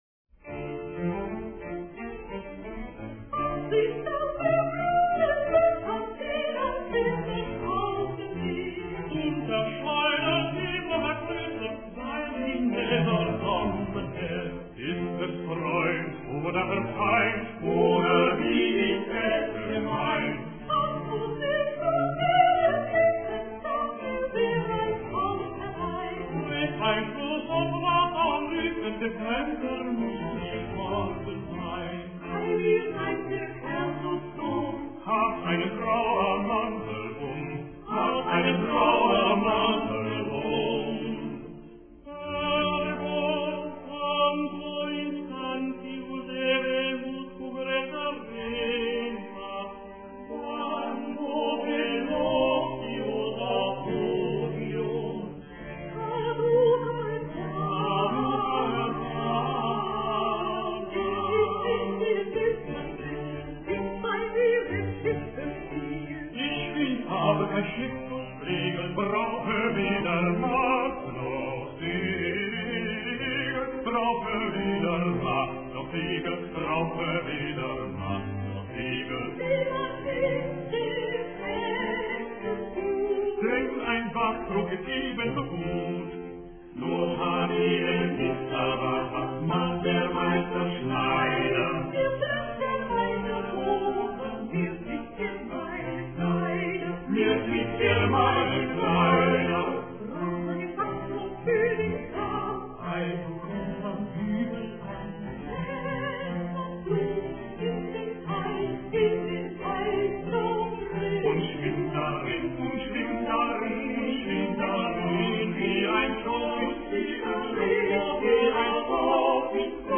mp3 mono 8kbps
Sacred Vocal Works ....